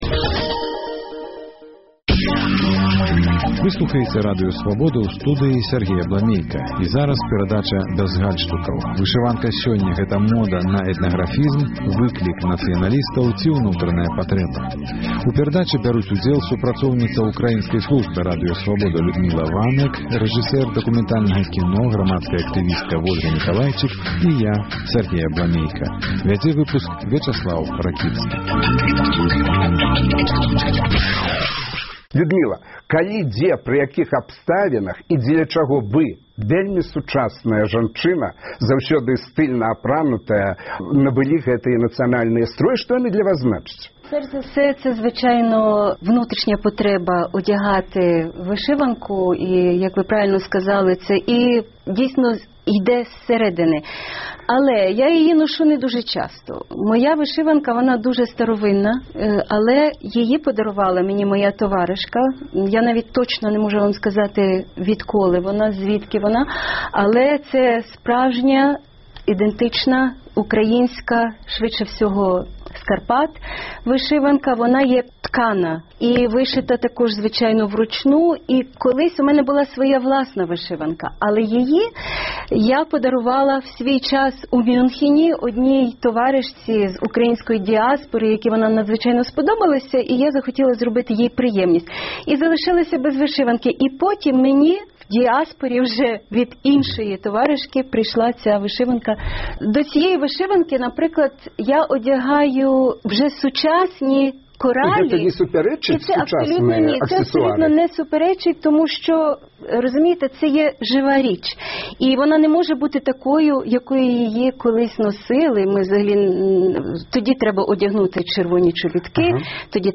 Абмеркаваньне гарачых тэмаў у студыі Свабоды.